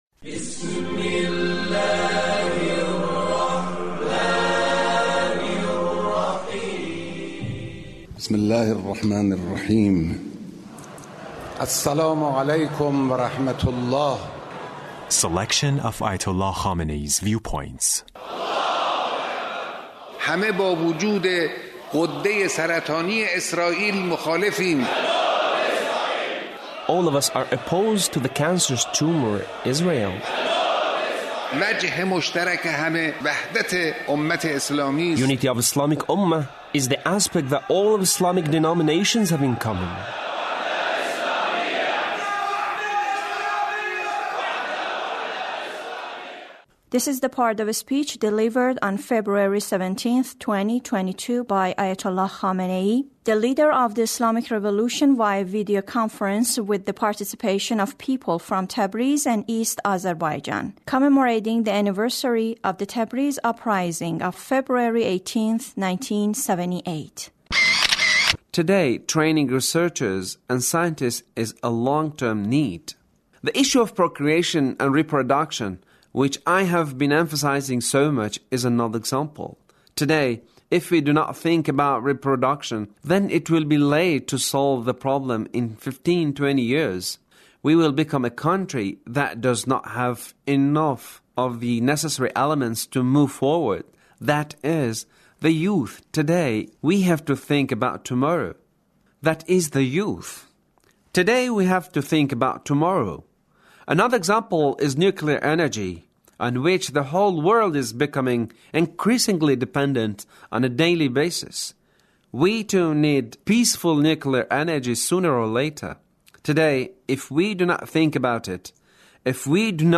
Leader's speech (1334)